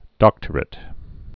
(dŏktər-ĭt)